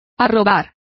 Complete with pronunciation of the translation of entranced.